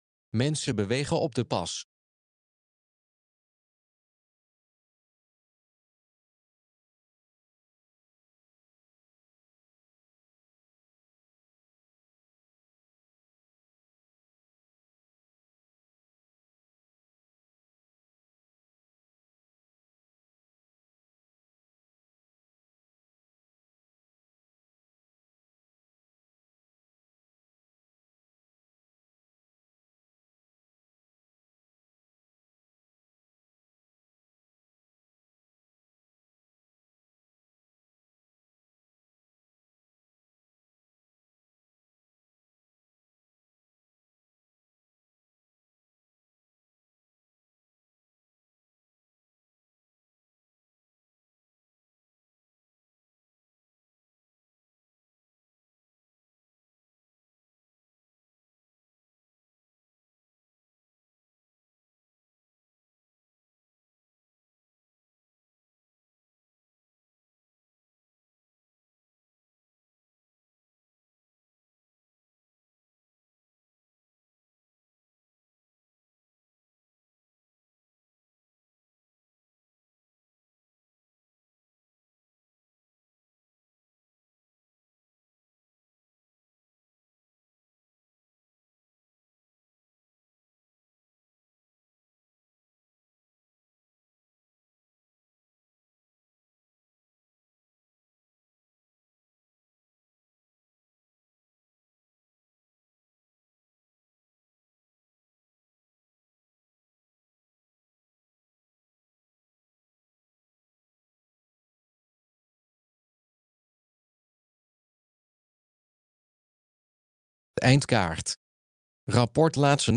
De presentatie van het advies werd ingeleid door een beweegsessie van 65-plussers van de Vitality Club.
Zie hieronder een filmpje met impressie van de aanbieding van het advies aan de staatssecretaris en de presentatie van het advies in Nieuwspoort.